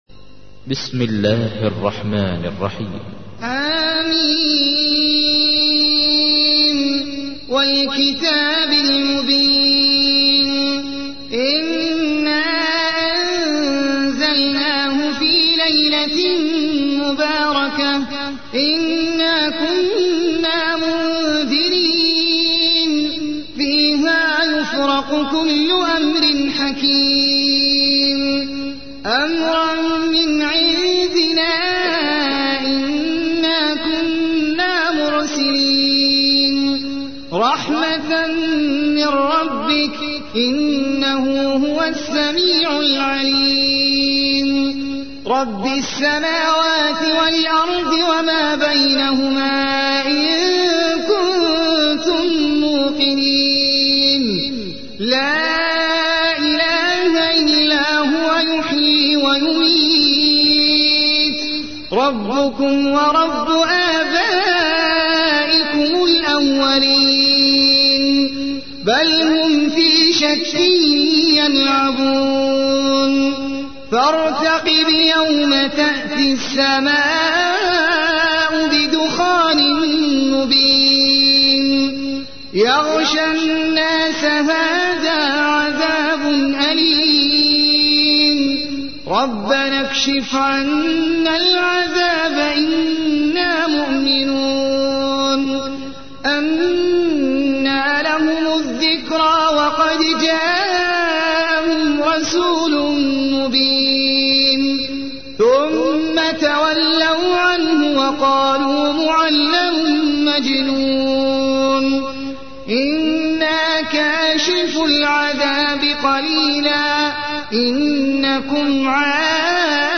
تحميل : 44. سورة الدخان / القارئ احمد العجمي / القرآن الكريم / موقع يا حسين